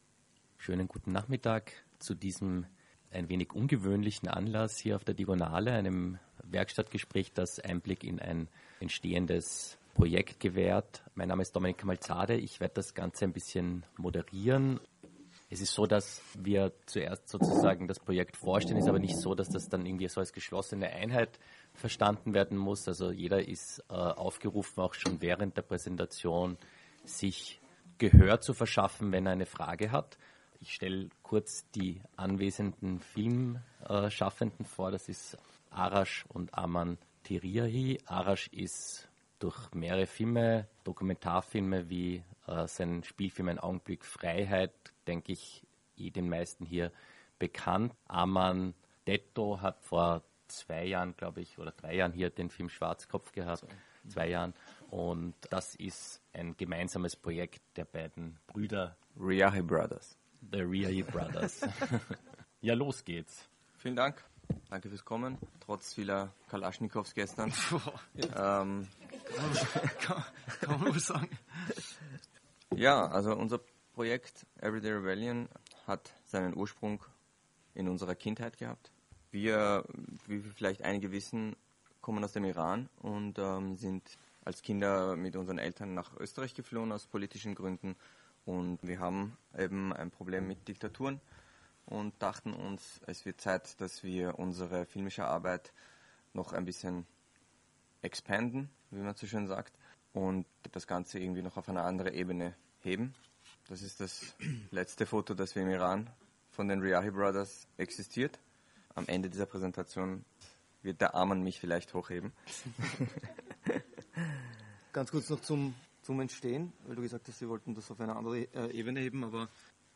Während der Diagonale 2013 fanden verschiedene Diskussionen im Space04 statt.